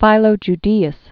(fīlō j-dēəs, -dā-) also Philo of Alexandria fl. early first century AD.